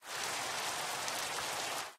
minecraft / sounds / ambient / weather / rain6.ogg
rain6.ogg